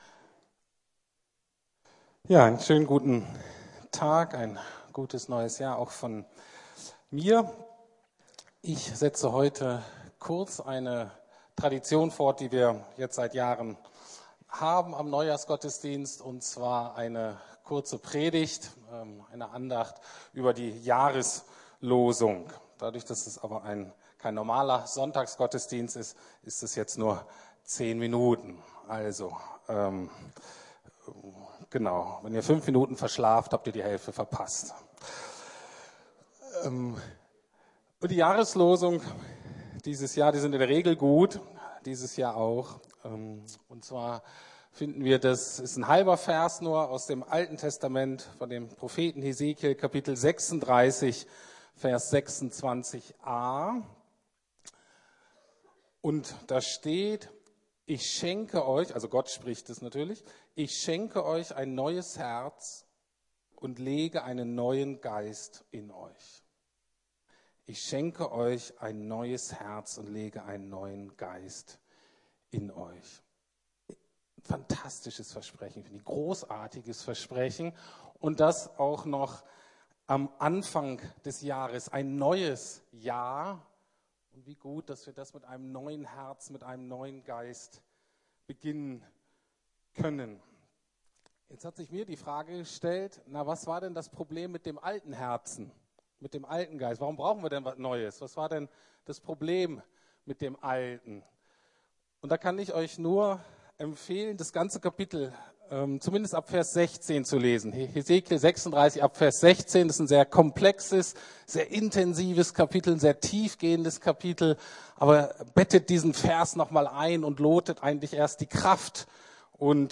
Anbetungsgottesdienst (Jahreslosung 2017)